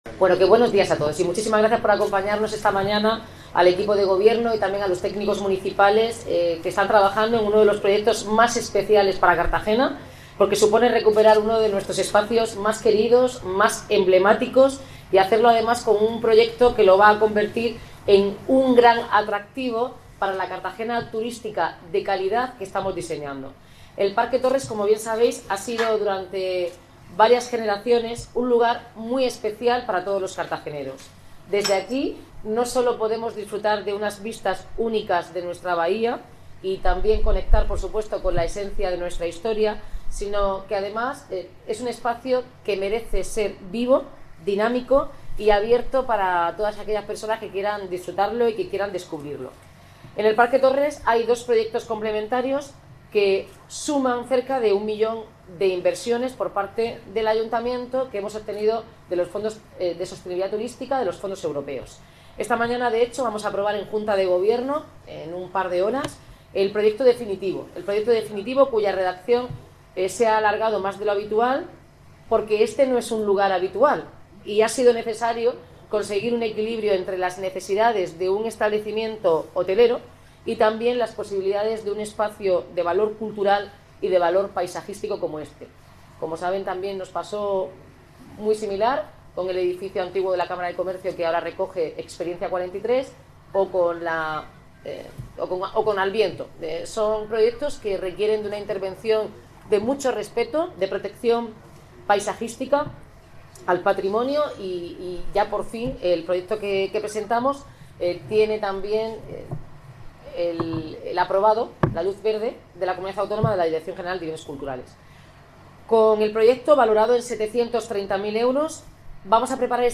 Audio: Presentación del proyecto de remodelación de la cafetería y accesibilidad del Parque Torres por parte de la alcaldesa, Noelia Arroyo (MP3 - 16,07 MB)